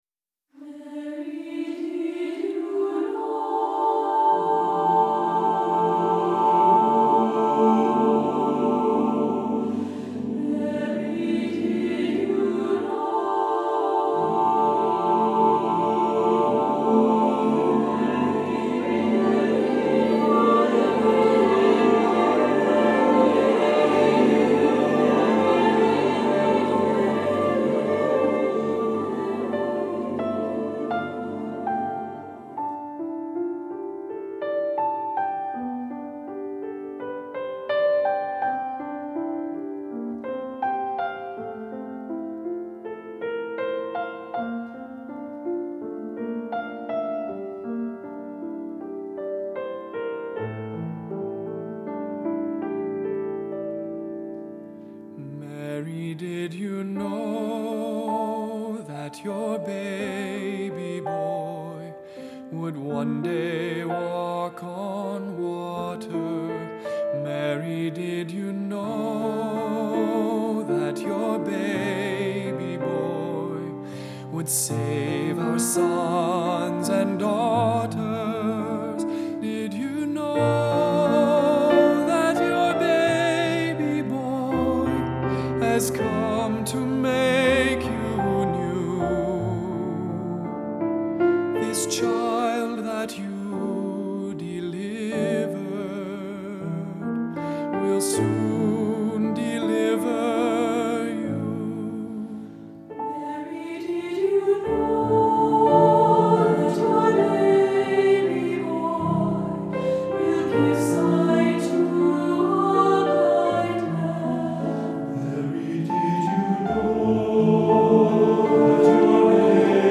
Voicing: Instrumental Pak